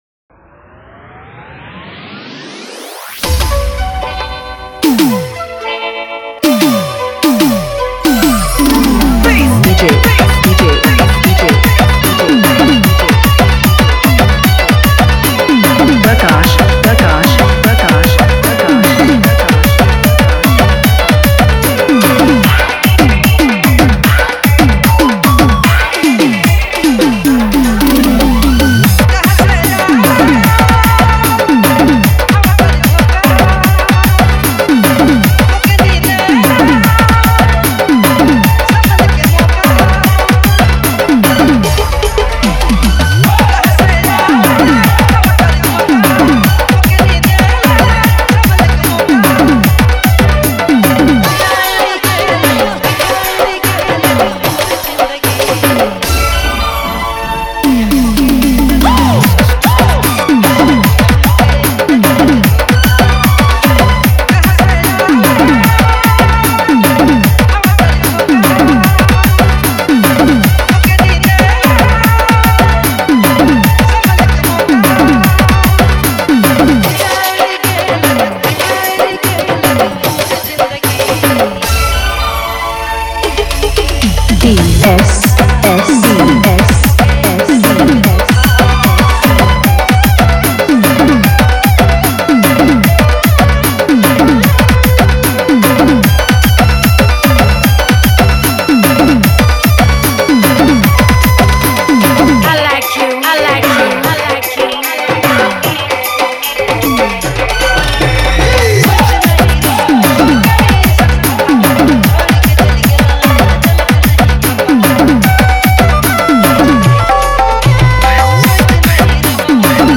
Nagpuri song